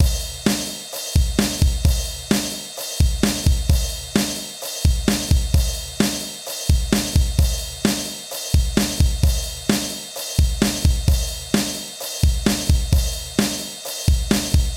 原声鼓循环 " 朋克摇滚乐 140 bpm
描述：长朋克岩槽140 bpm
标签： 循环 WAV 循环 节奏 朋克摇滚 击败了 声鼓
声道立体声